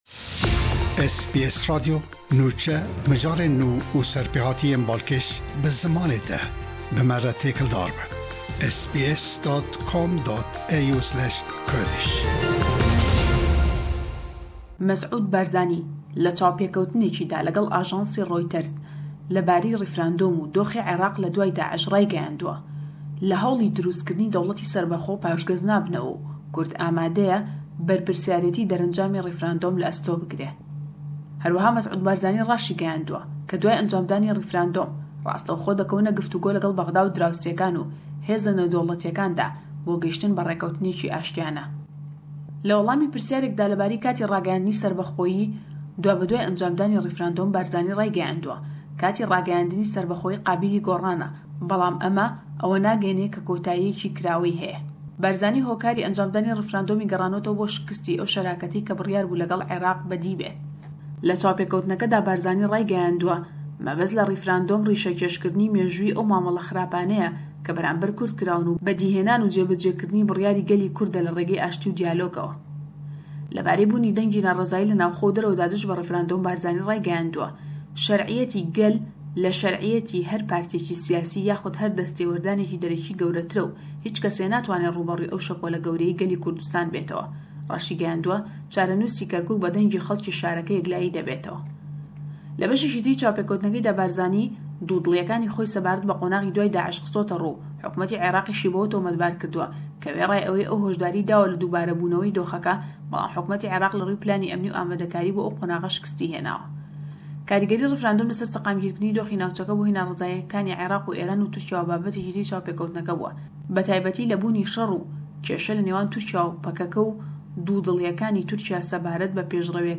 Rêfrendom, rewsha Îraqê pishtî Daish û pirsgirêkên aborî di raporta ji Slêmanî de